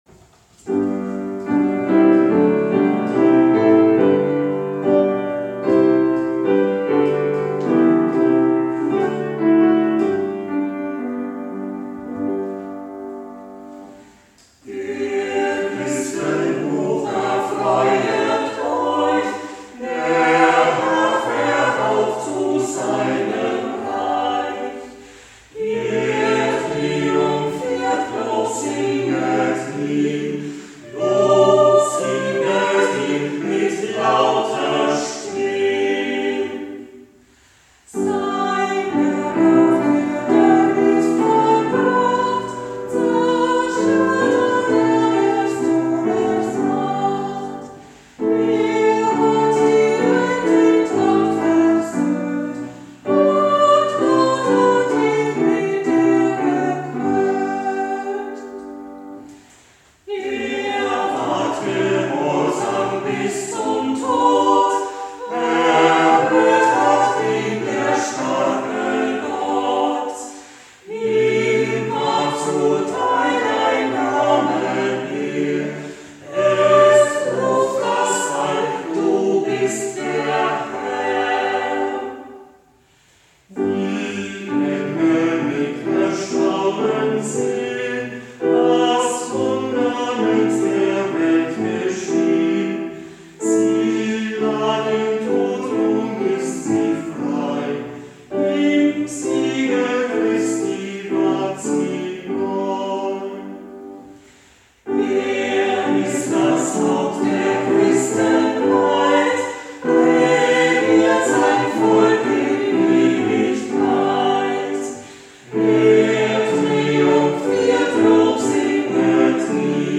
Gottesdienst zu Christi Himmelfahrt
aus der ref. Erlöserkirche,